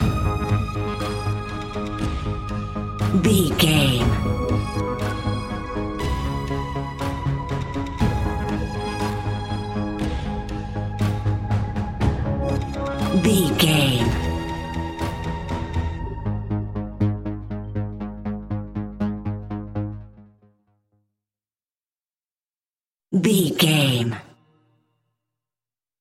In-crescendo
Thriller
Aeolian/Minor
ominous
dark
eerie
horror music
Horror Pads
horror piano
Horror Synths